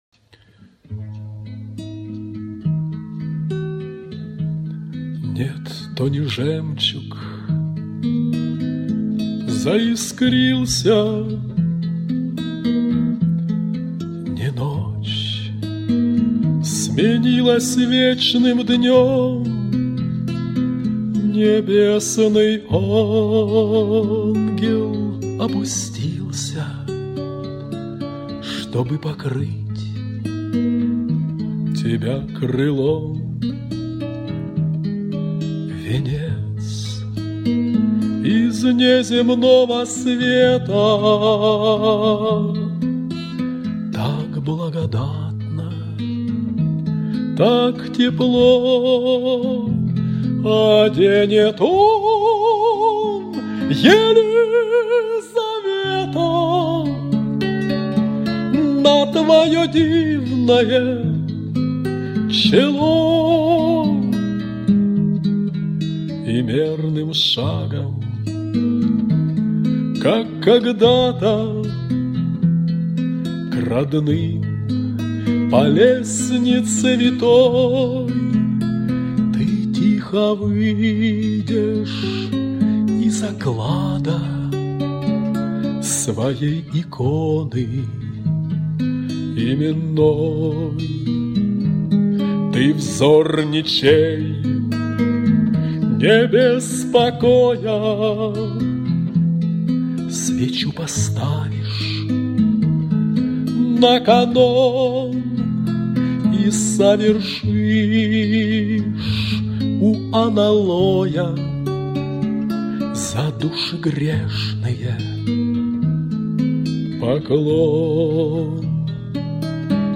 Авторская песня